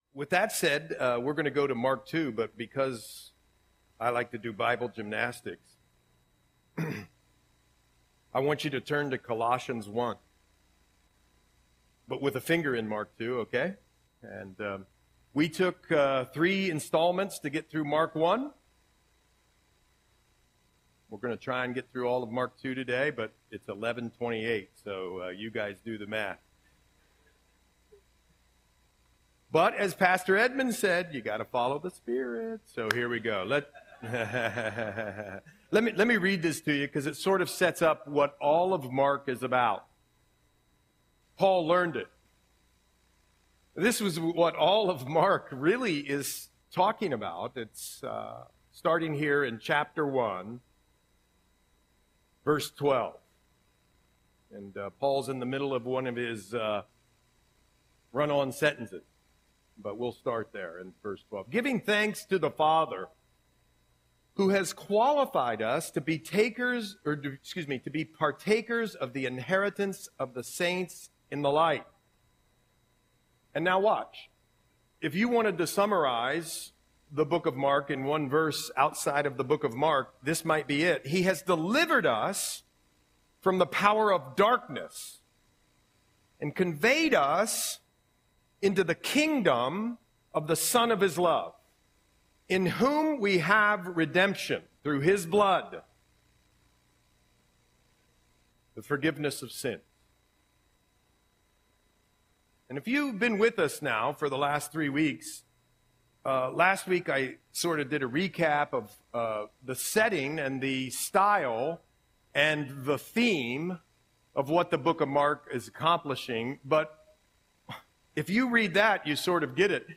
Audio Sermon - October 27, 2024